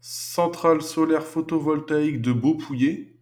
Fichier audio de prononciation du projet Lingua Libre